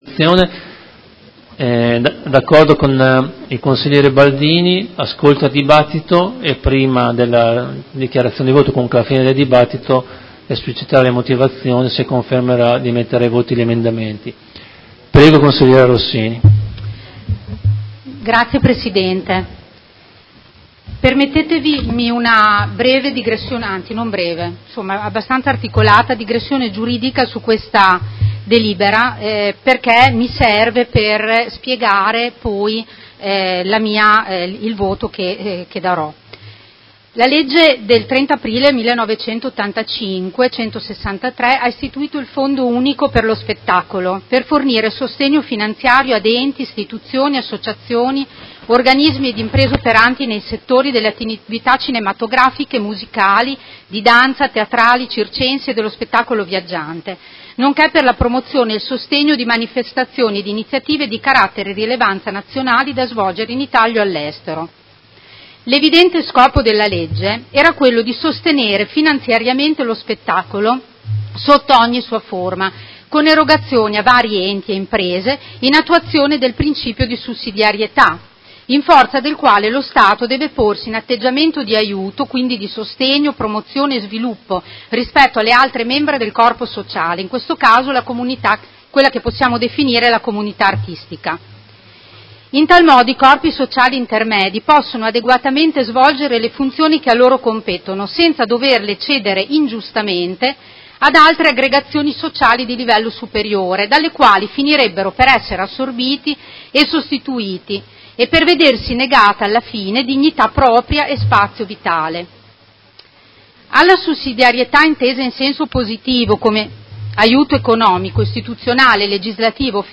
Elisa Rossini — Sito Audio Consiglio Comunale